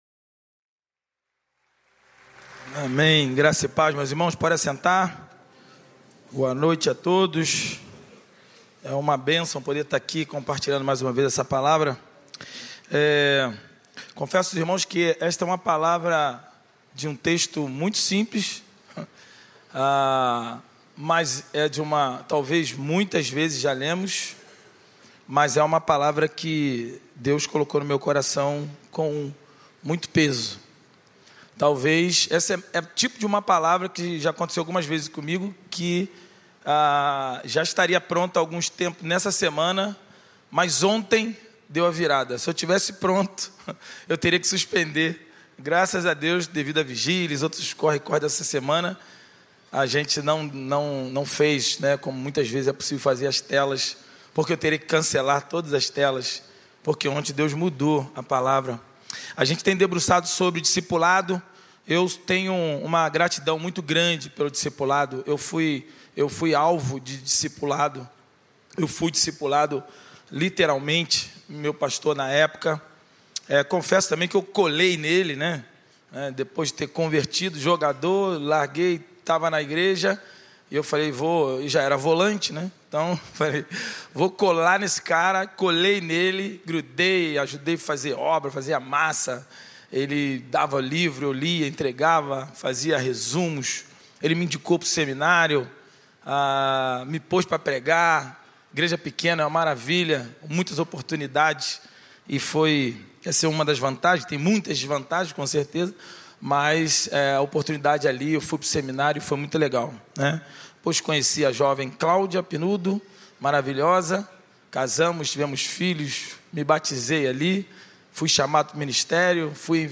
Mensagem apresentada
na Igreja Batista do Recreio.